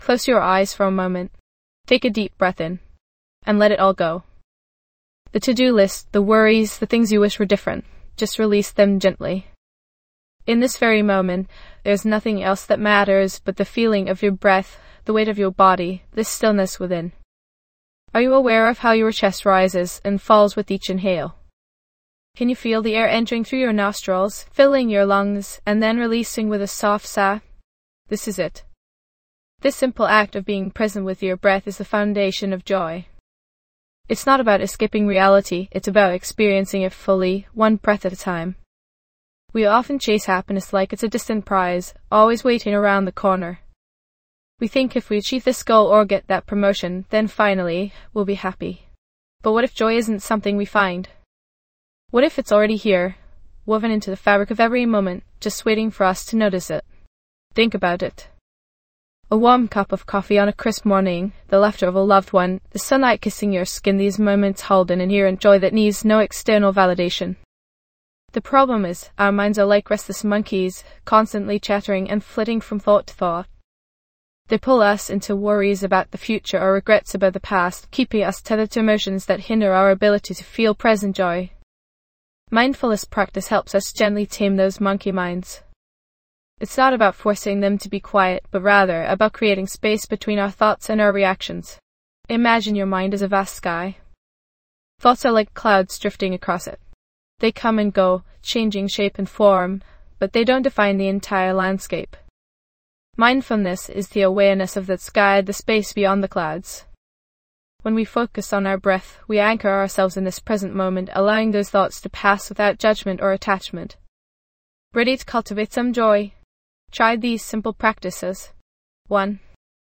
Episode Description:.Unleash the power of mindfulness as we guide you through a transformative micro meditation designed to help you find joy in the present moment. This immersive experience will bring you closer to self-awareness, promote inner peace, and cultivate gratitude for lifes simple pleasures.